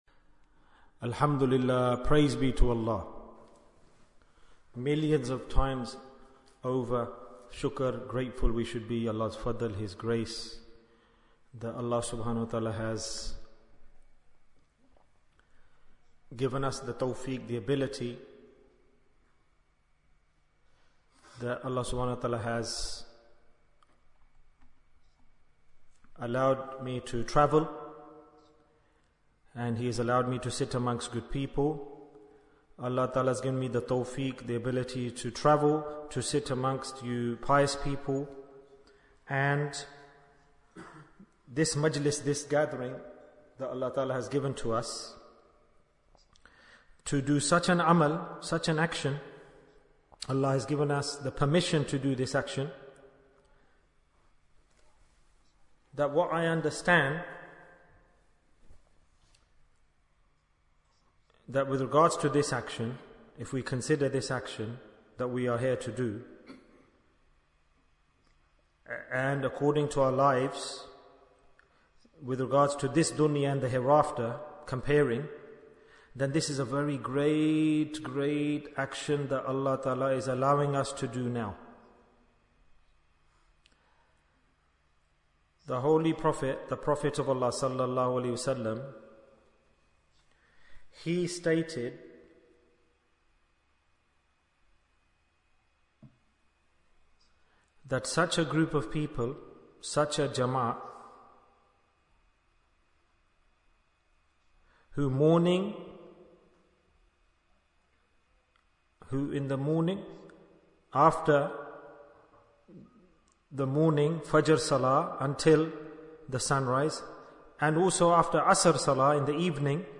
Majlis-e-Dhikr in Bradford Bayan, 22 minutes19th May, 2024